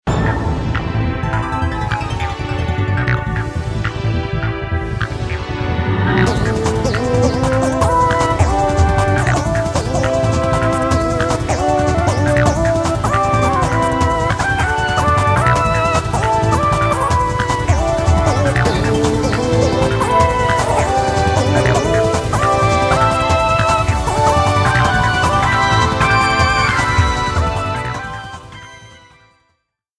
Reduced length to 30 seconds, with fadeout.